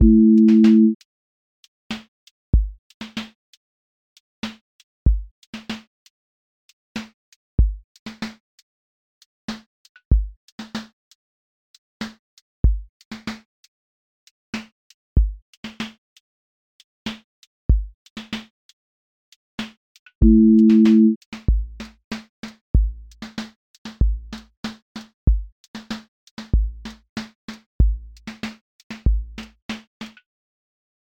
QA Listening Test neo-soul Template: neo_soul_lilt
Neo-soul live lilt pocket 30s
• voice_kick_808
• voice_snare_boom_bap
• voice_hat_rimshot
• tone_warm_body